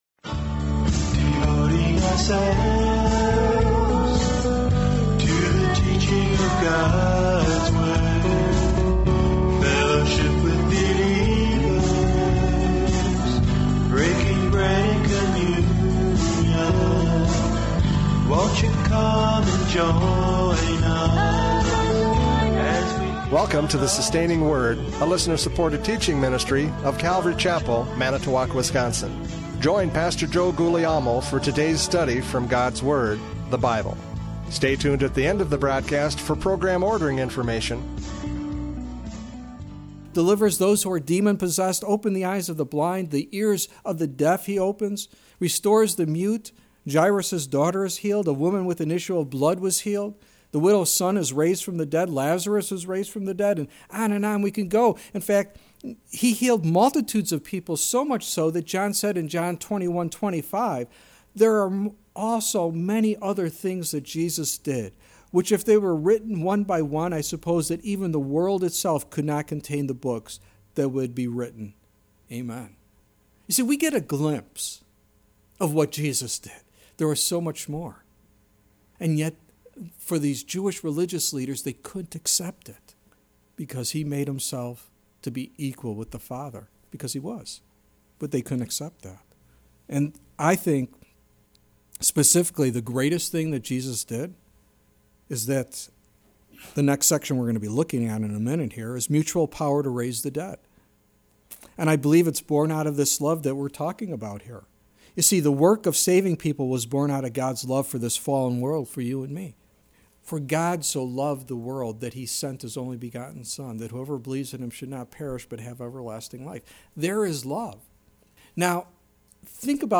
John 5:19-21 Service Type: Radio Programs « John 5:19-21 Equality in Power!